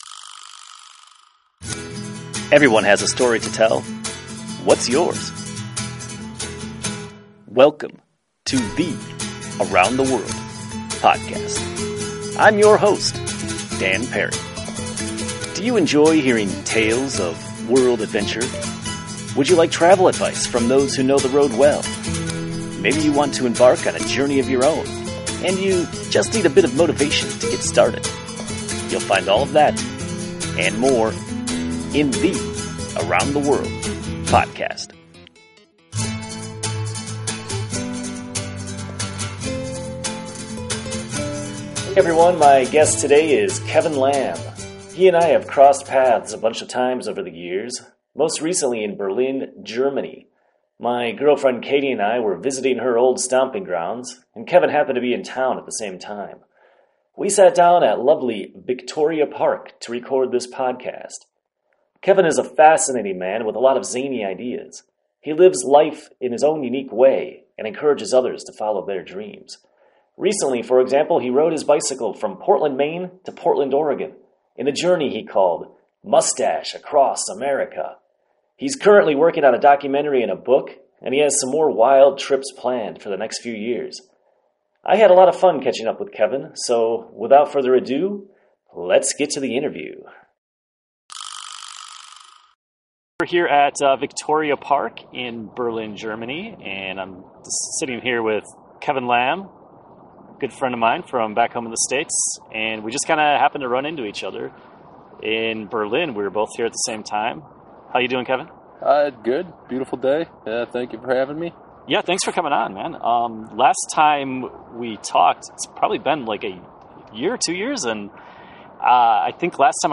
A podcast interview